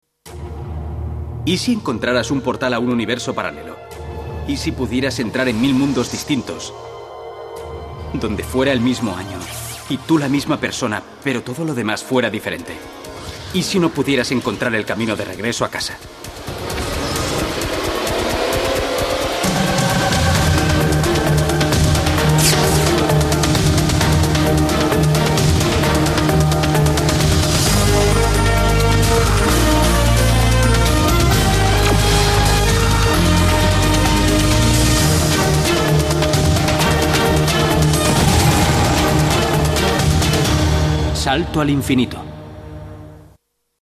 Dieser Text aus der 4. Staffel wird von der spanischen Synchronstimme von Quinn Mallory gesprochen.
sliders_intro_s4_spanien.mp3